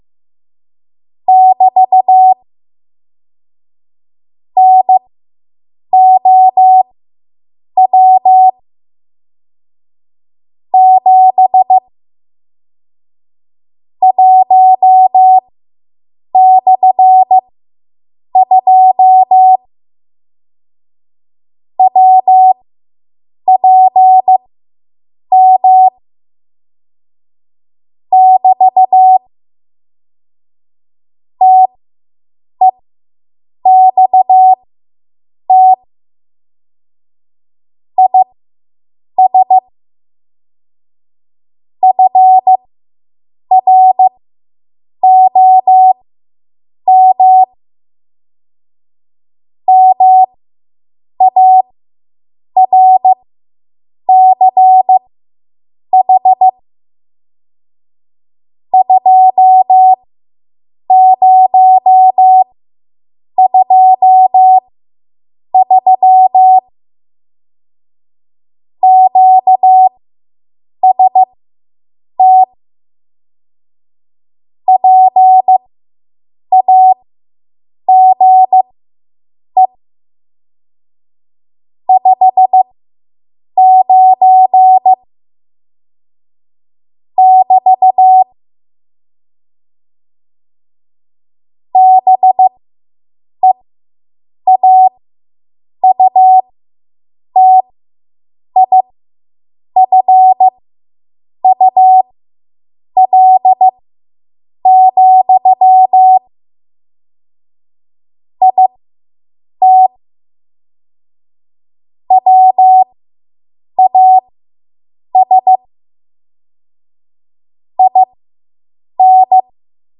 7.5 WPM Code Practice Archive Files
Listed here are archived 7.5 WPM W1AW code practice transmissions for the dates and speeds indicated.
You will hear these characters as regular Morse code prosigns or abbreviations.